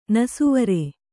♪ nasuvare